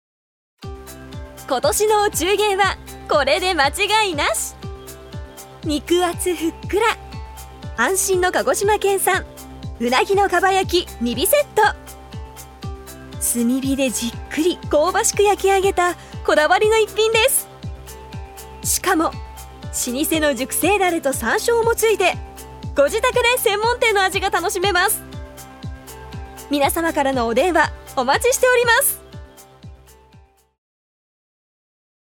女性タレント
ナレーション５